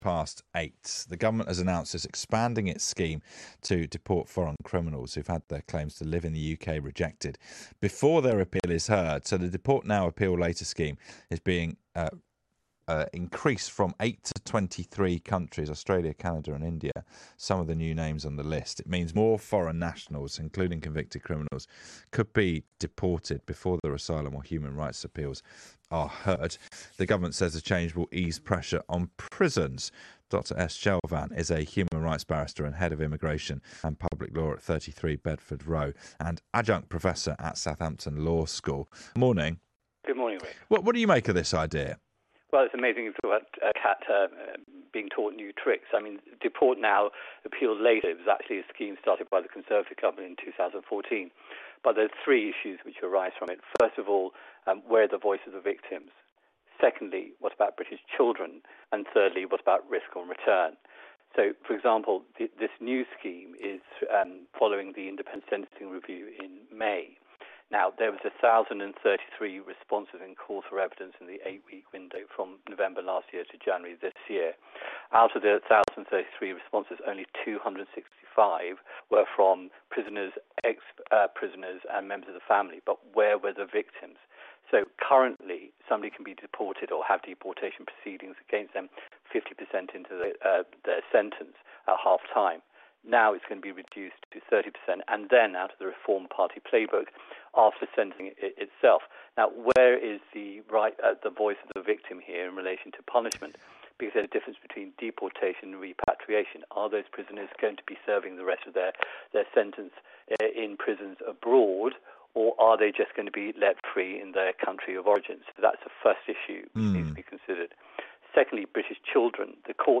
here (interview broadcast on 11 August 2025, recording kindly supplied by BBC Radio 5 live).